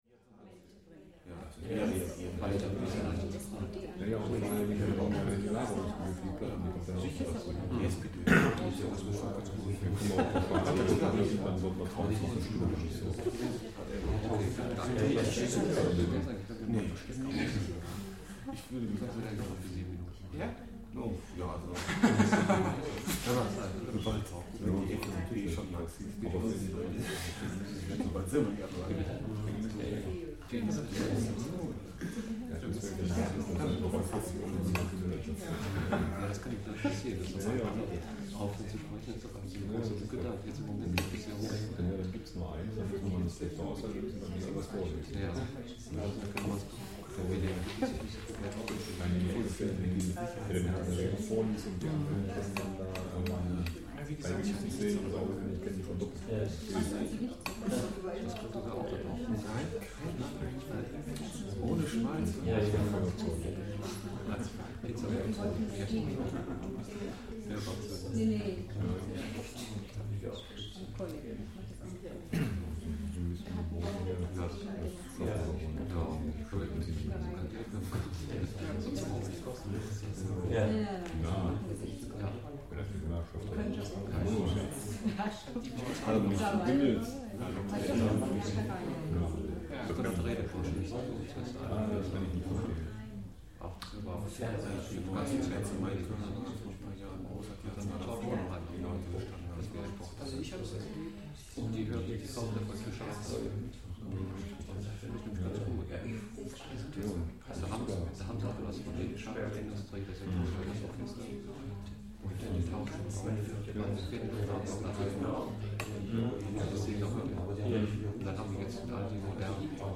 7. Размеренный разговор зрелых людей